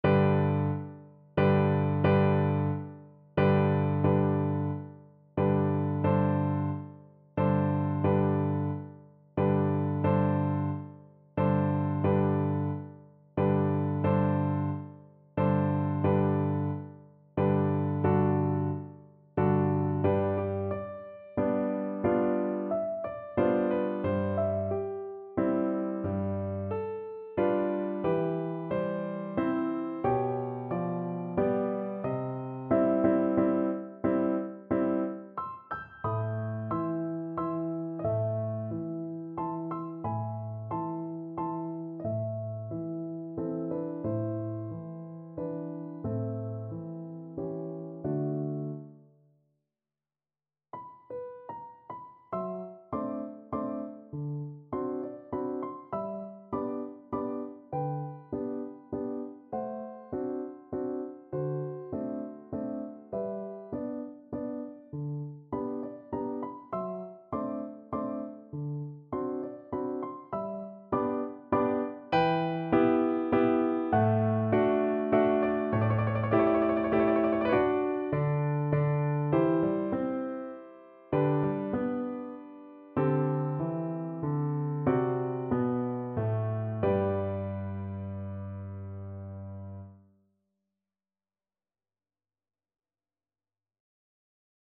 3/4 (View more 3/4 Music)
~ = 90 Allegretto moderato
Classical (View more Classical Viola Music)